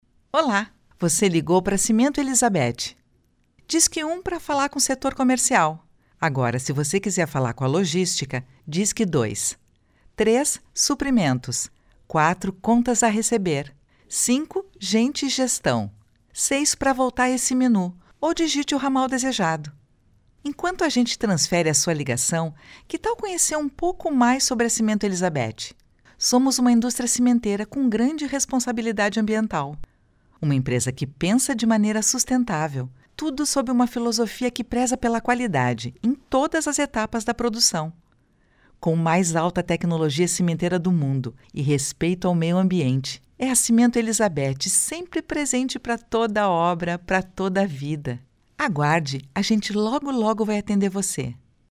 Feminino
Voz Padrão - Grave 00:51